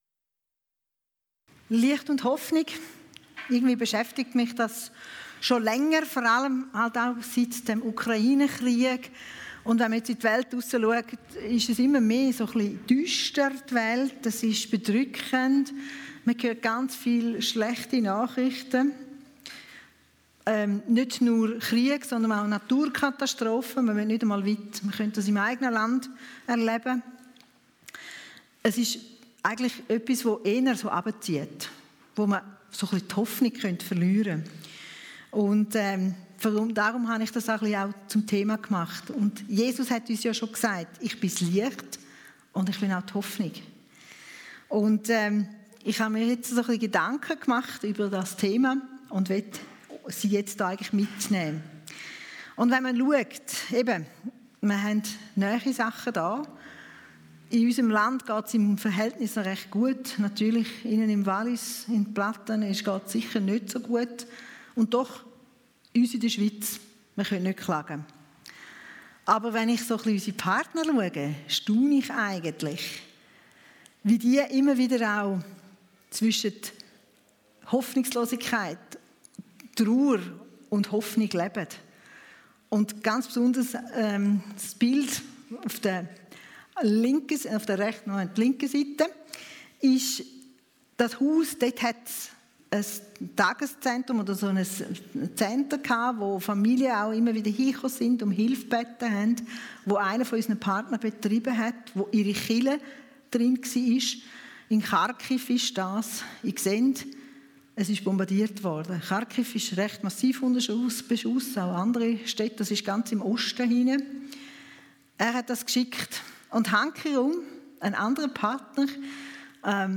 Missionsgottesdienst Licht und Hoffnung